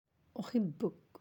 (‘uhibuk)
uhibuk.aac